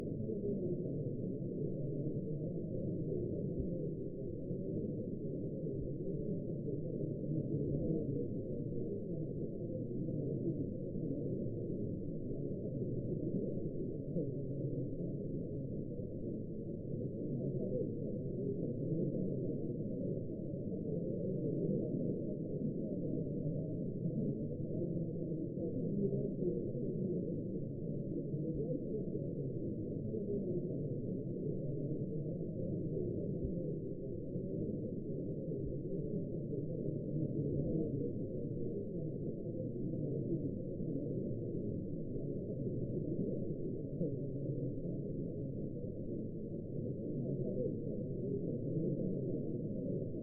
Звук разговора толпы людей — так слышат мир глухонемые